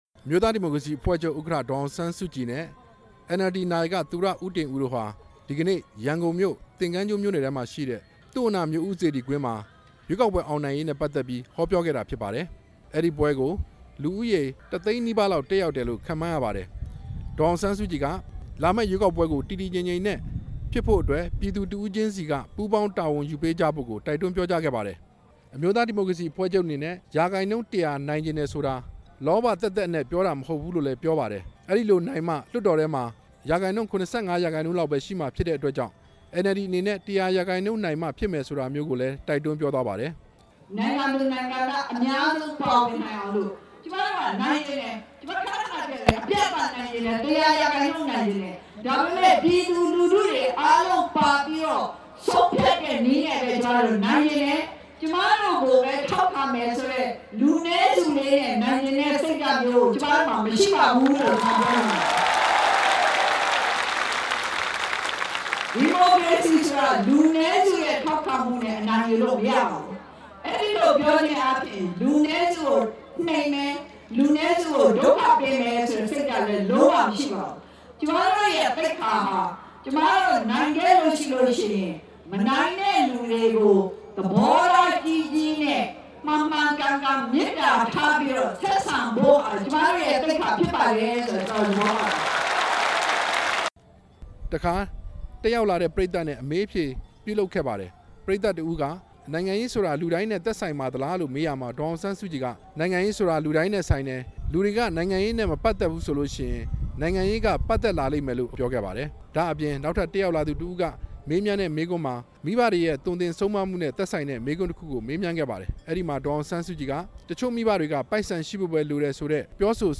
သုဝဏ္ဏမြို့ဦးစေတီကွင်းမှ ဒေါ်အောင်ဆန်းစုကြည် ဟောပြောပွဲအကြောင်းတင်ပြချက်